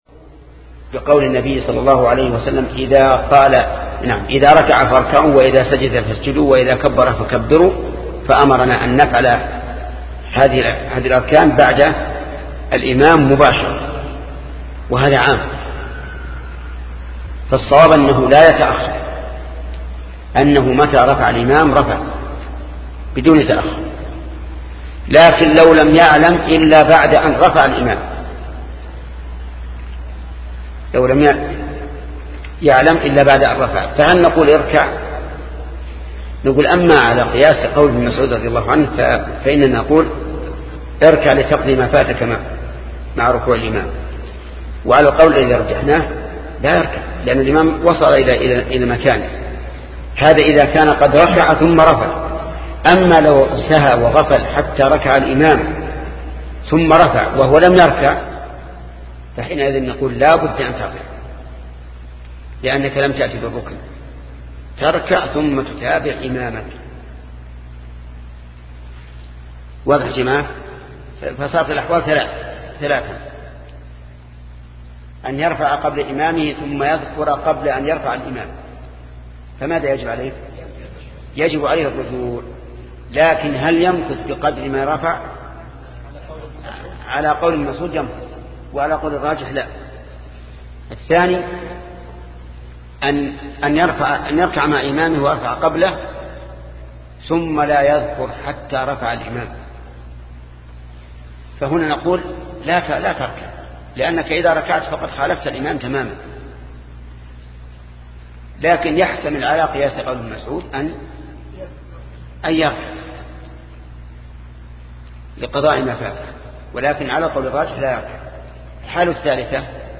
شرح صحيح البخاري - الشيخ محمد بن صالح العثيمين